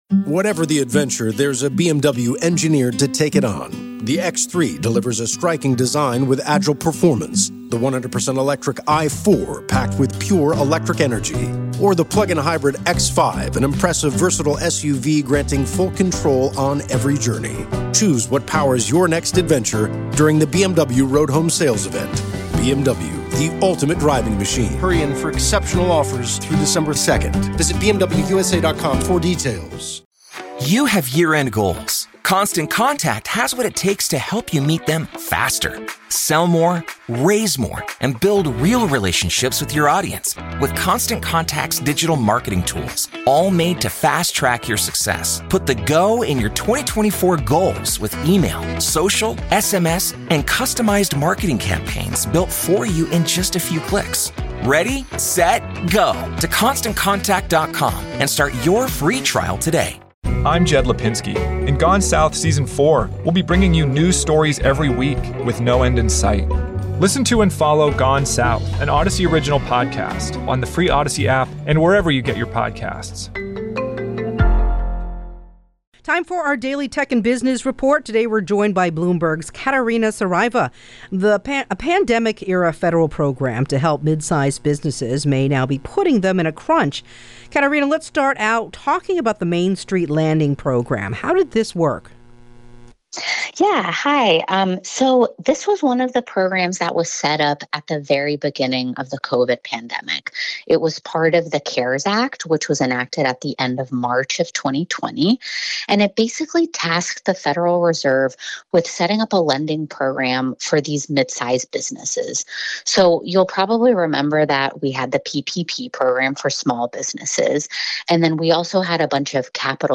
This is KCBS Radio's daily Tech and Business Report.